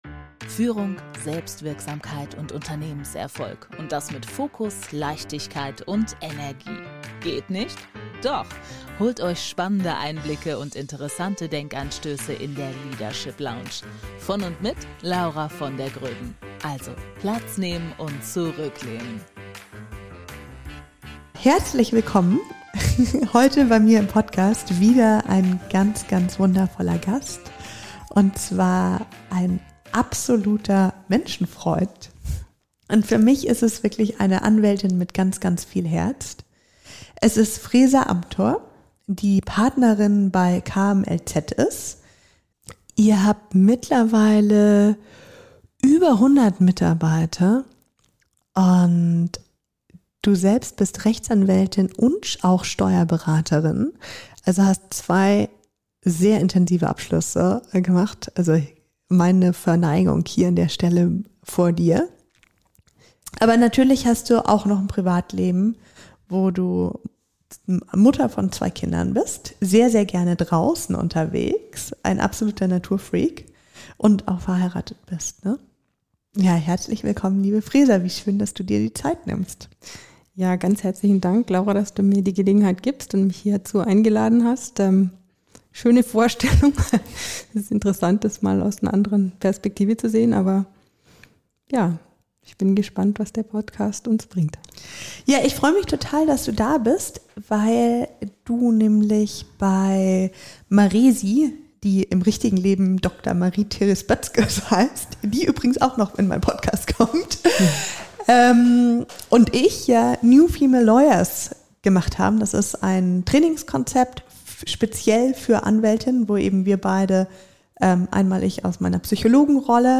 New Female Lawyers: Dein Weg zu mehr Stärke, Klarheit und Sichtbarkeit im Anwaltsalltag | Interview